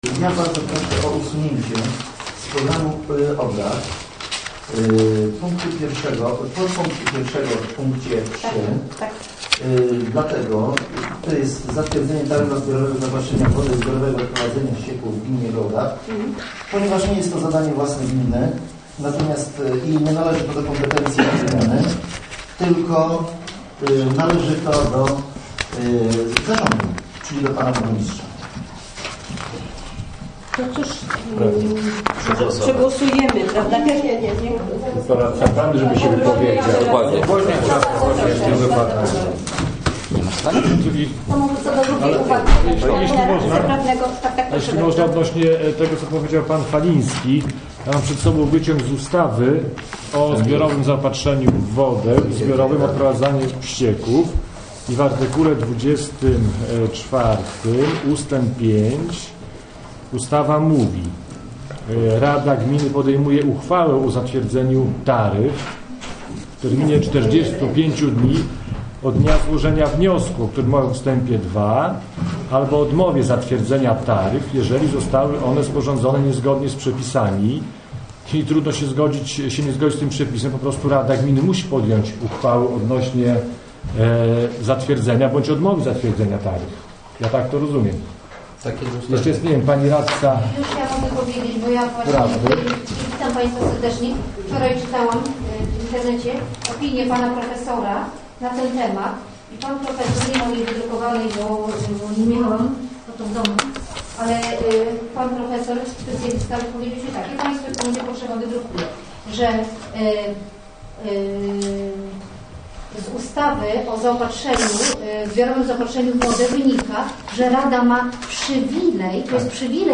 fragment dyskusji